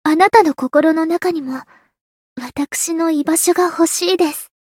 灵魂潮汐-安德莉亚-情人节（摸头语音）.ogg